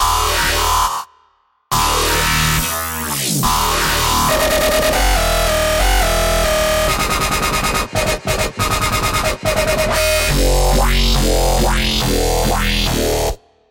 标签： 140 bpm Dubstep Loops Bass Wobble Loops 2.31 MB wav Key : D